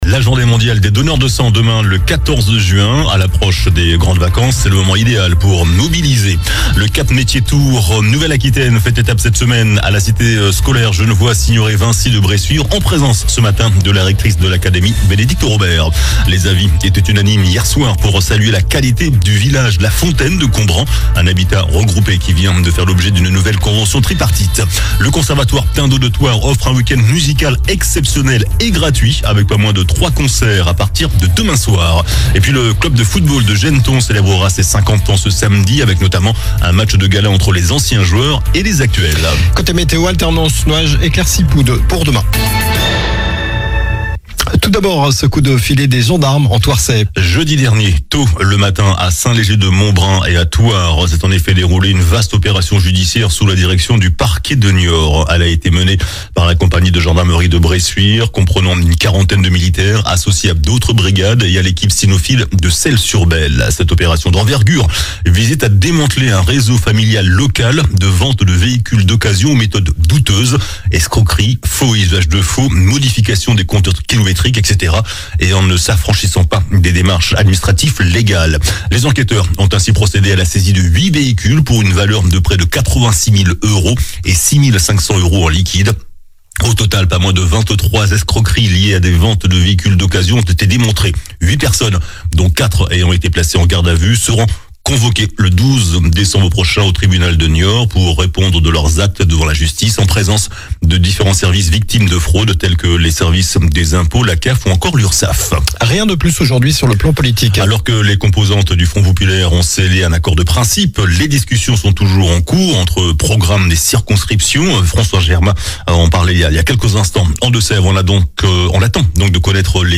infos locales 13 juin 2024